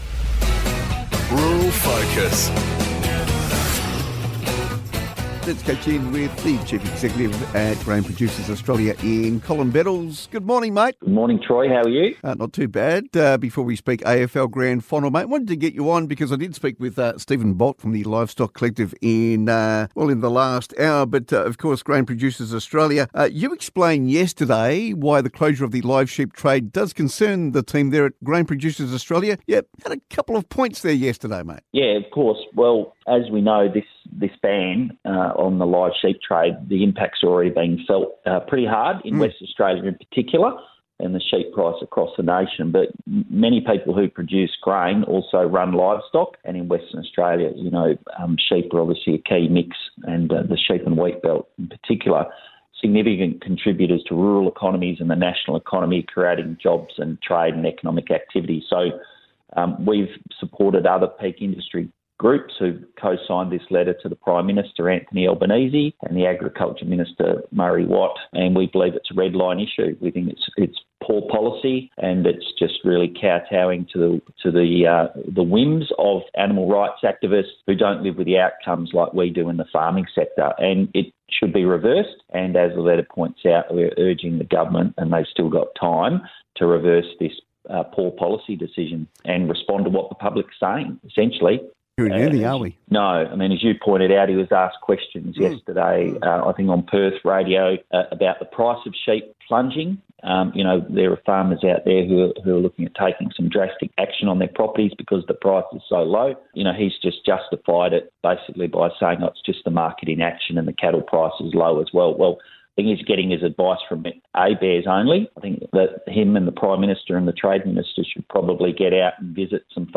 RURAL FOCUS | GPA Interview: Live Sheep Export Ban